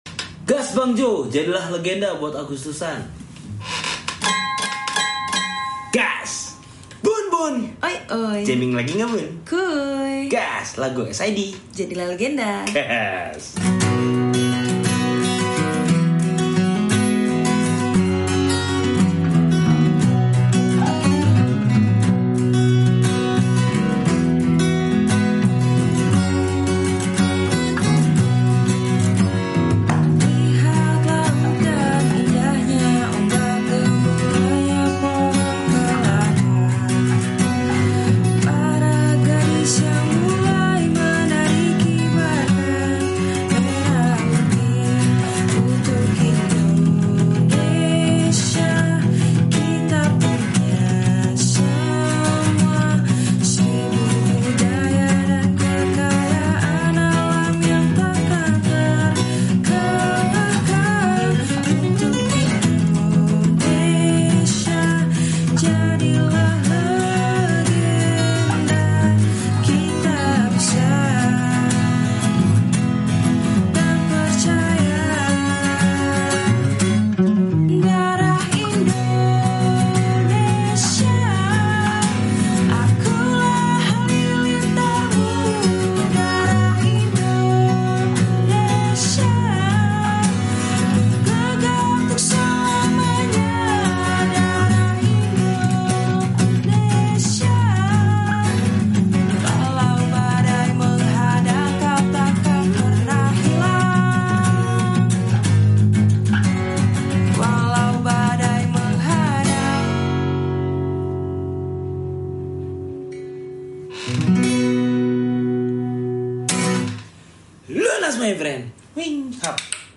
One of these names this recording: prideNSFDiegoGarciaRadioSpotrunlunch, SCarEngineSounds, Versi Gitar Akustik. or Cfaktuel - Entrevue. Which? Versi Gitar Akustik.